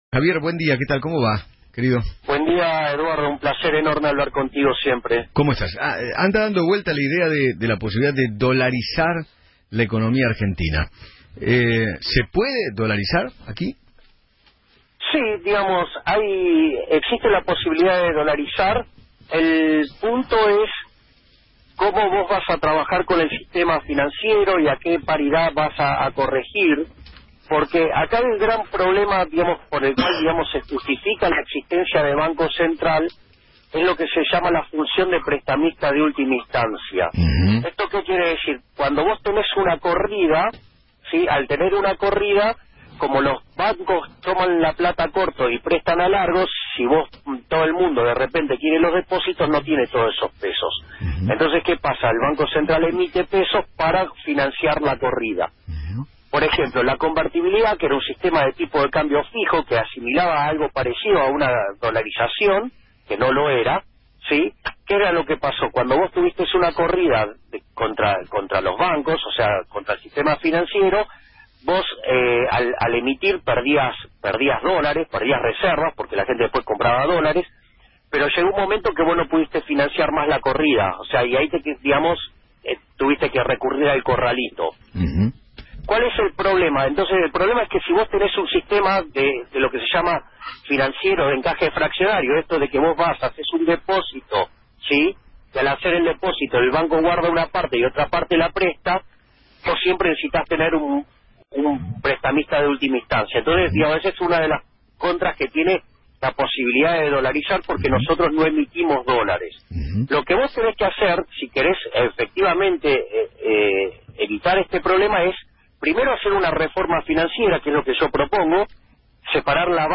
Eduardo Feinmann, conductor: “Es muy dificil, explícalo de otra manera.”
Javier Milei, Economista: “Me parece razonable que se piense en dolarizar.